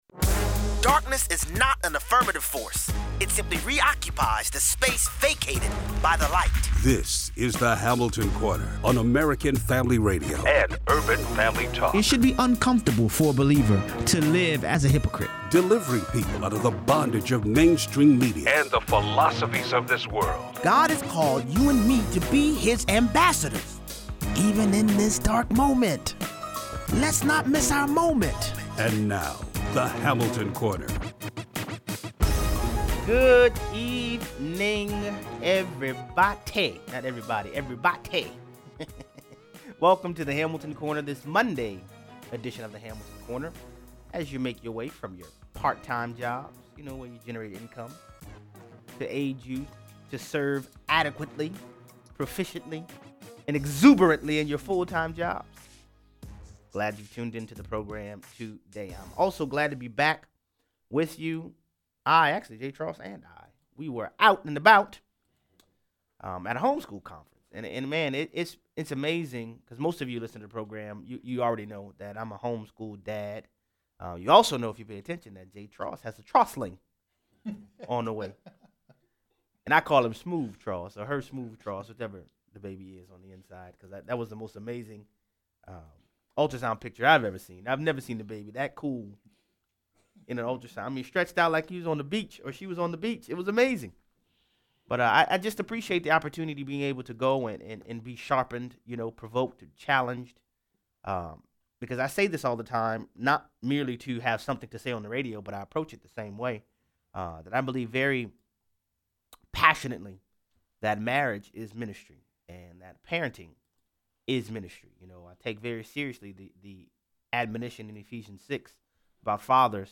Delayed obedience is disobedience. 0:23 - 0:40: North Korean papers report that Kim Jong Un “desperately” wants a peace treaty with the US. Sounds like there’s a different tone coming from the DPRK these days. 0:43 - 0:60: The U.N. continues its cover up of a global scandal where their aid workers require sexual favors in exchange for aid. Callers weigh in.